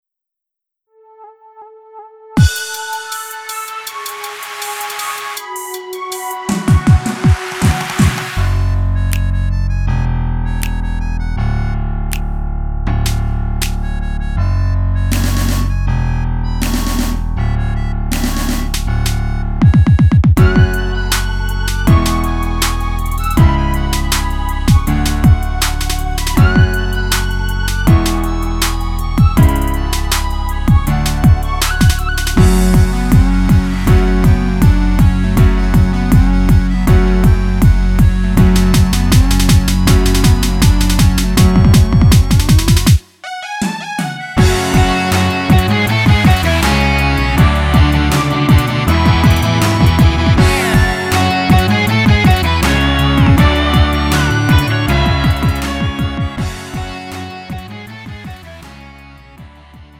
음정 원키 3:07
장르 가요 구분